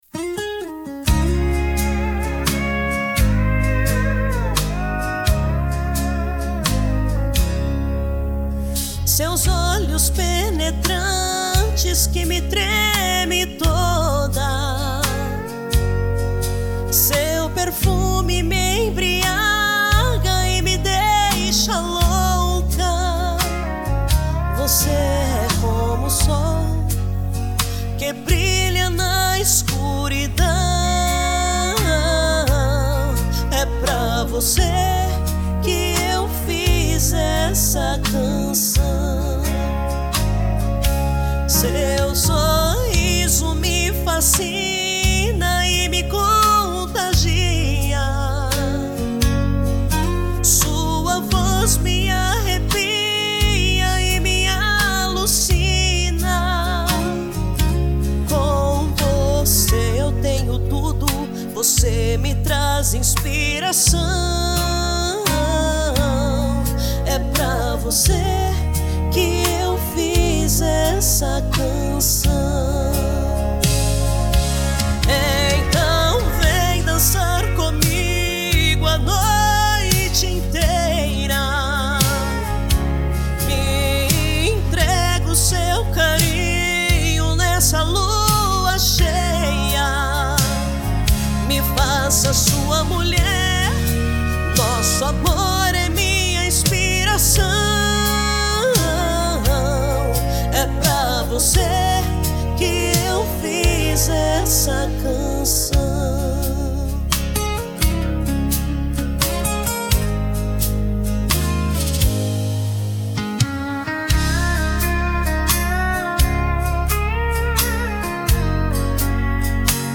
EstiloCountry